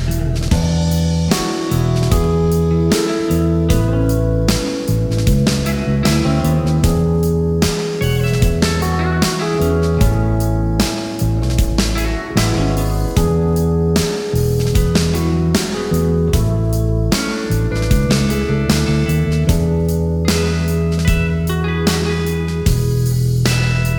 no Backing Vocals Rock 5:37 Buy £1.50